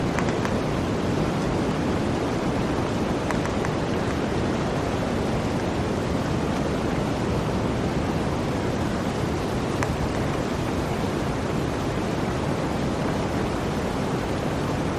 Forest Wind
Forest Wind is a free nature sound effect available for download in MP3 format.
338_forest_wind.mp3